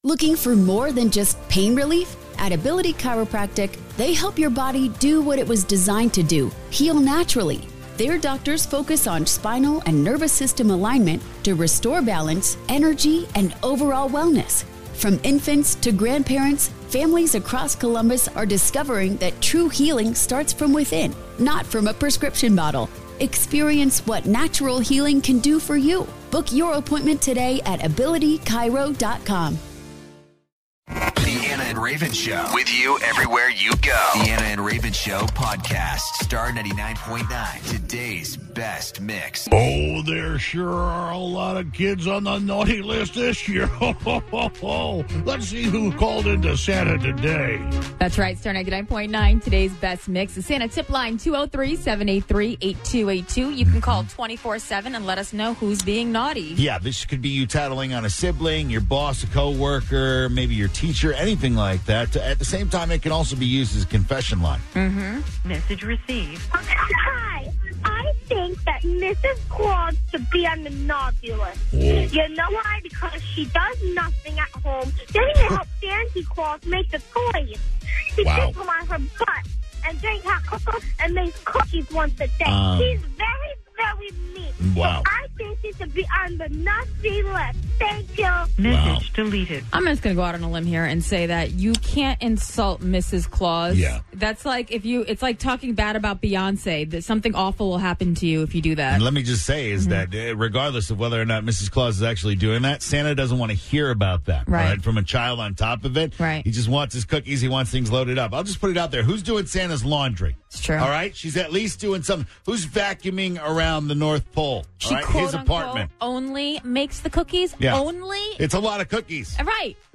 The Santa Tipline is always open to everyone's calls, including one caller who thinks that Mrs. Claus doesn't do a whole lot of anything up at the North Pole...